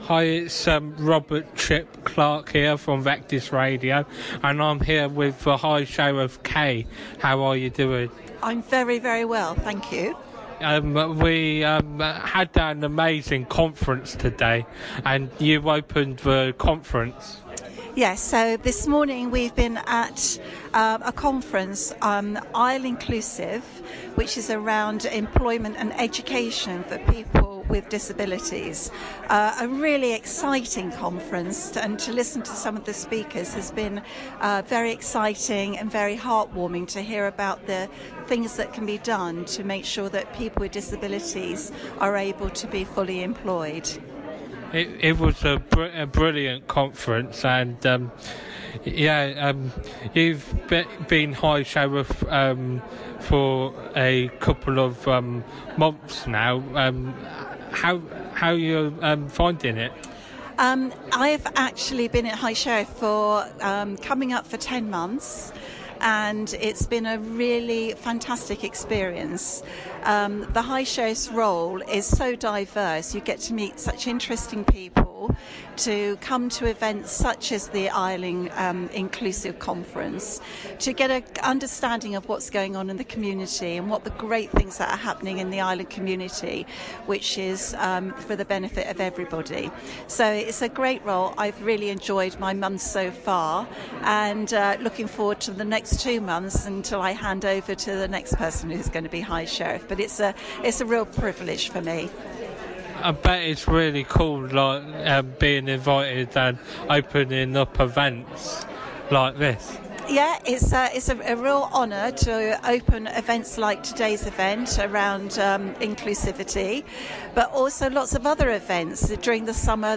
at The Inclusive Island Conference - Kay Marriott High Sheriff IOW Interview 2023